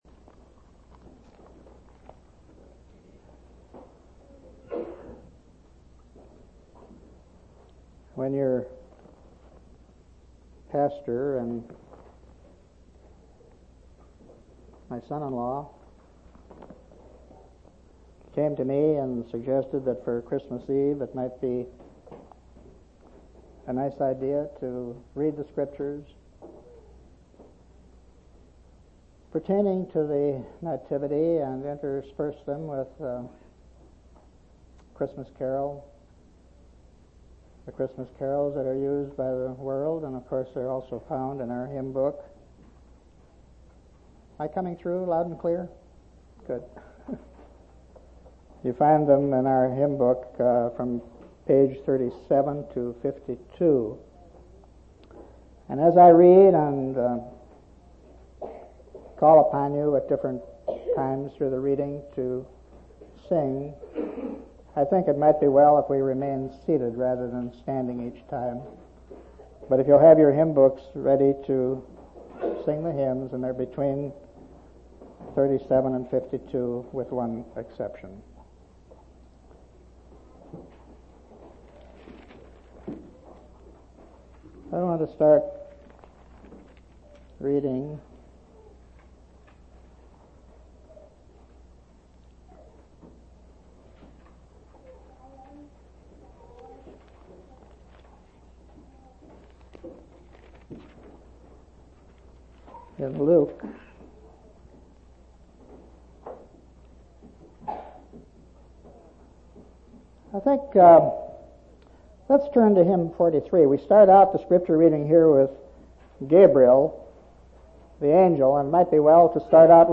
12/24/1989 Location: Temple Lot Local Event